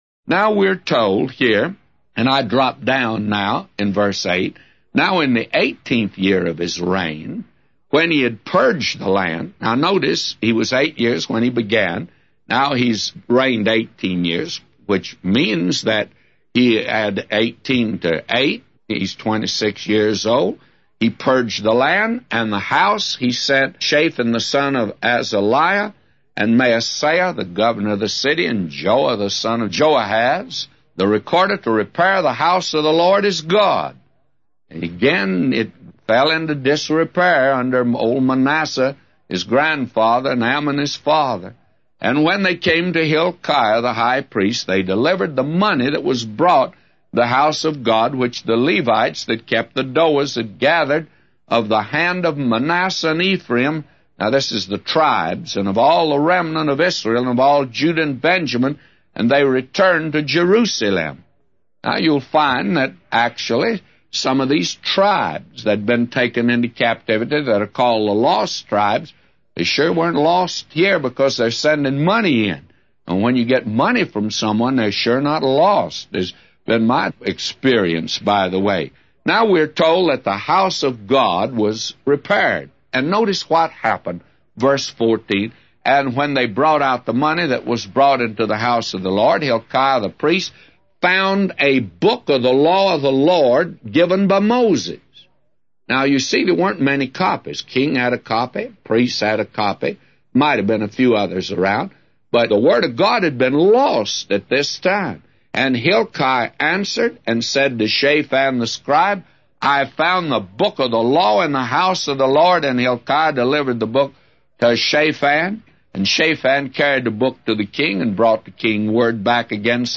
A Commentary By J Vernon MCgee For 2 Chronicles 34:8-999